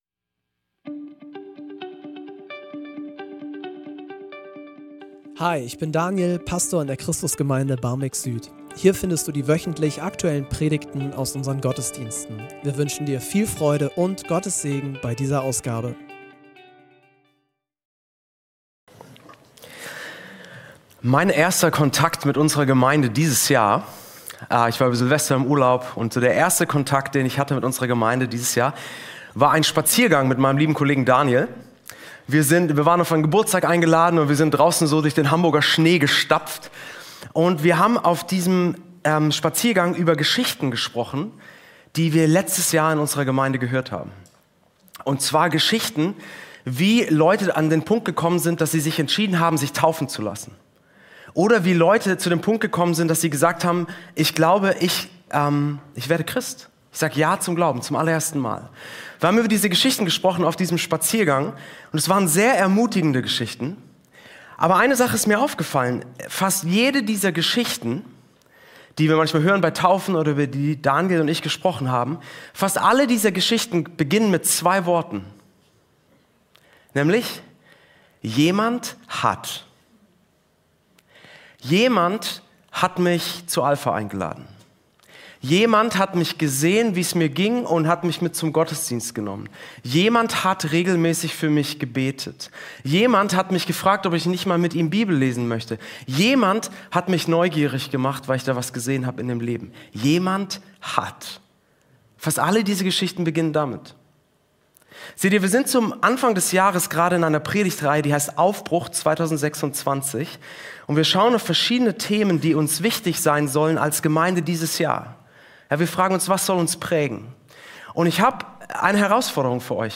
Predigtreihe